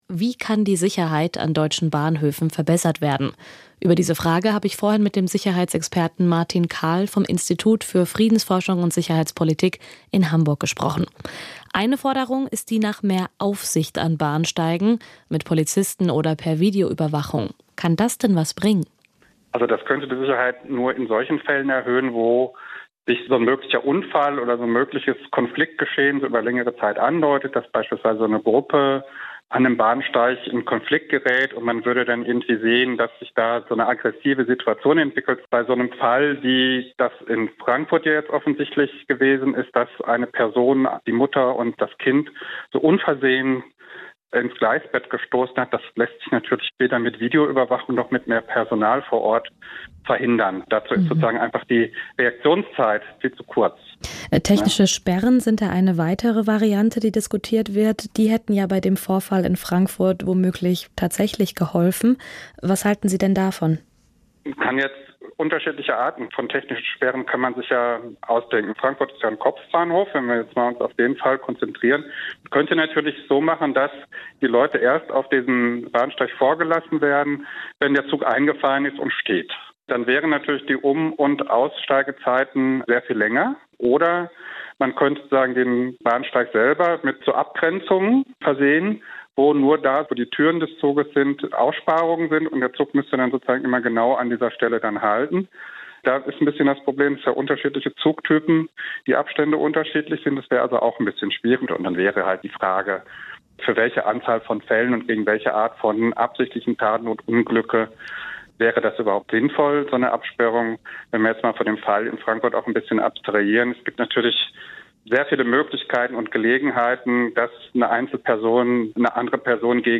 Sicherheit an deutschen Bahnhöfen - Interview SWR Aktuell